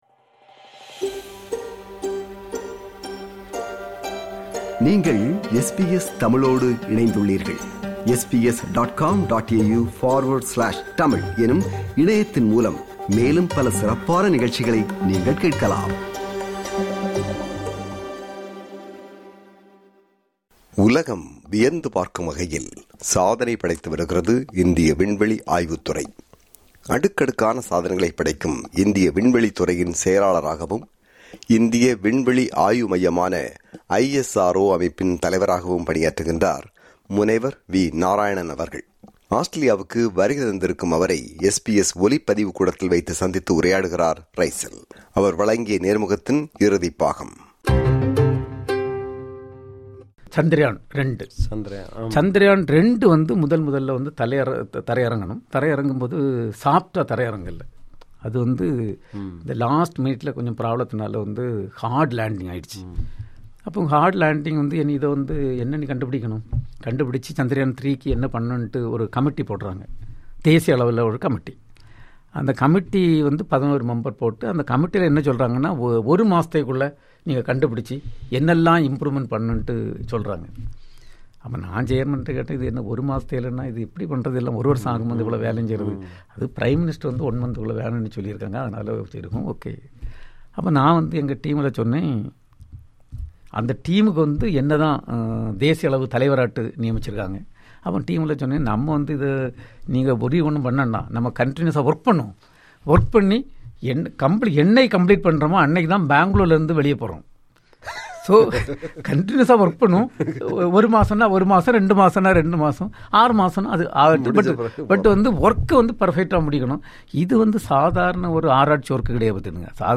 SBS ஒலிப்பதிவுகூடத்தில் வைத்து சந்தித்து உரையாடுகிறார்